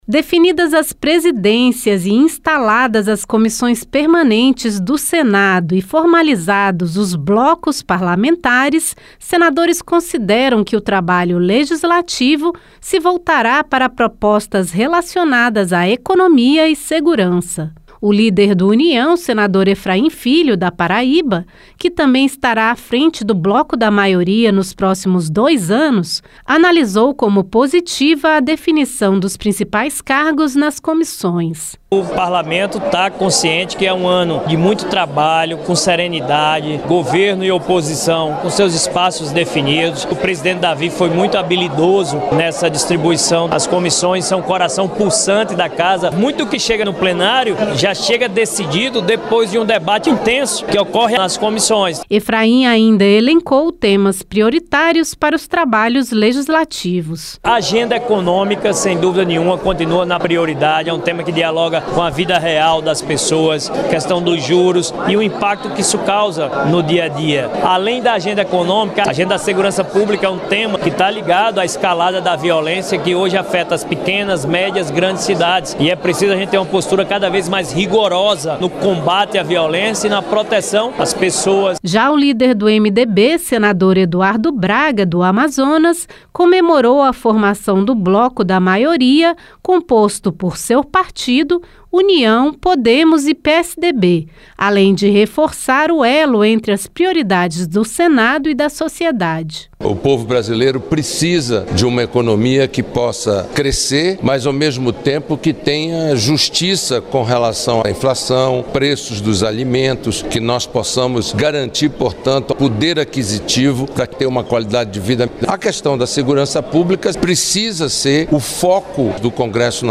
Os líderes do União, Efraim Filho (PB), e do MDB, Eduardo Braga (AM), manifestaram-se após a definição das presidências e instalação das comissões sobre temas que deverão ser destaque nos trabalhos legislativos.